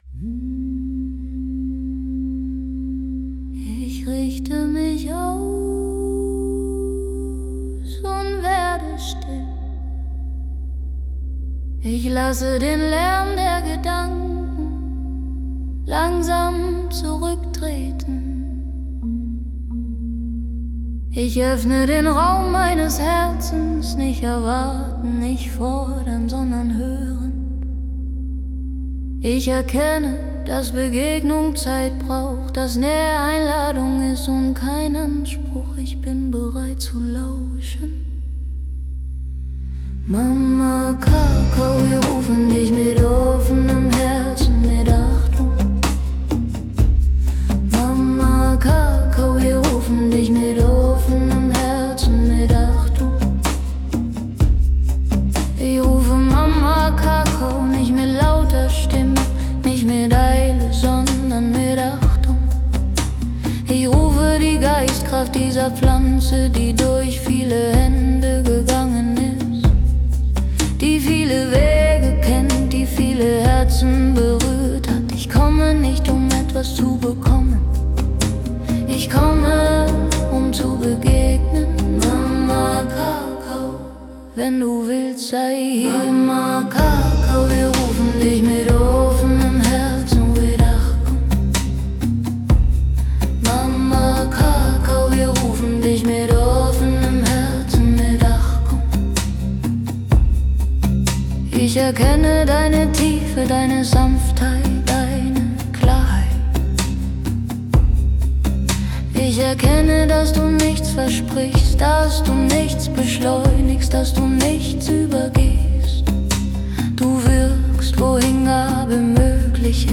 Die Lieder sind lang, ruhig, repetitiv und bewusst schlicht.
• klare Sprache, langsamer Puls